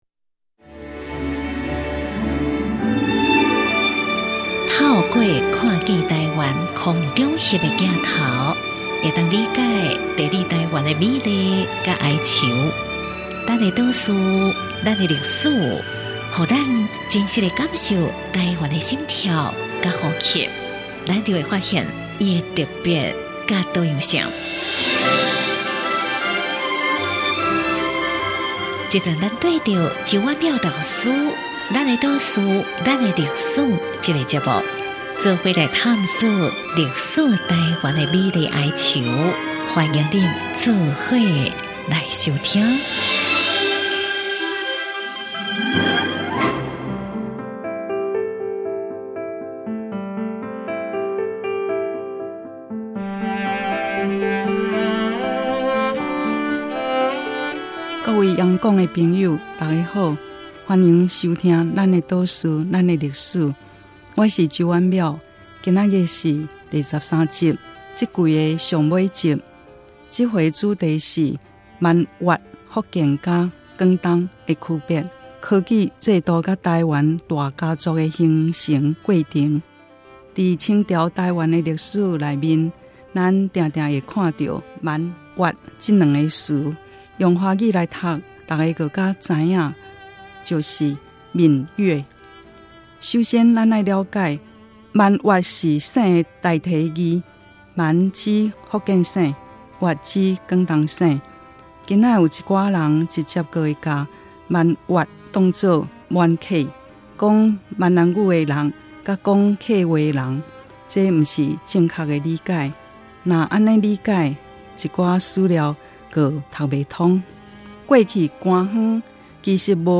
原文出處 節目名稱：Lán-ê 島嶼．lán-ê歷史 播出時間：2017年12月25日 主講者：周婉窈老師 Lán ê 島嶼．lán ê 歷史 透過「看見臺灣」空拍鏡頭，我們終於理解「地理臺灣」的美麗與哀愁。